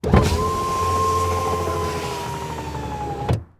windowdown.wav